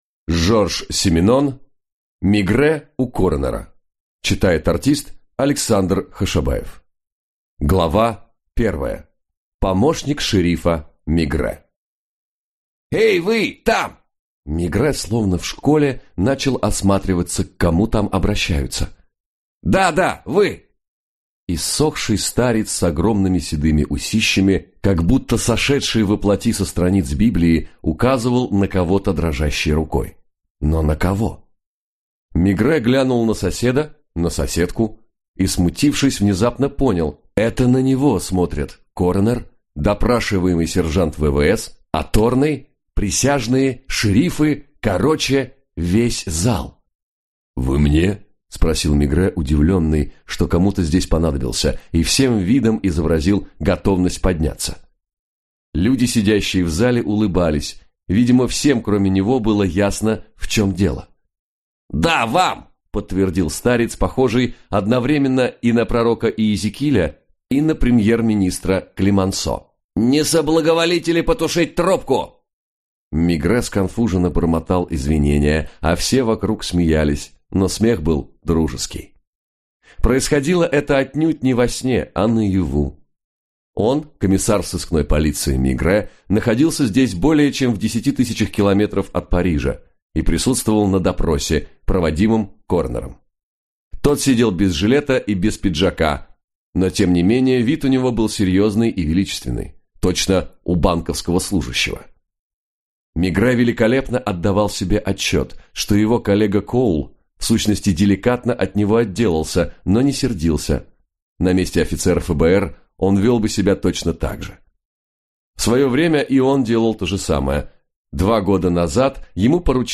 Аудиокнига Мегрэ у коронера | Библиотека аудиокниг
Прослушать и бесплатно скачать фрагмент аудиокниги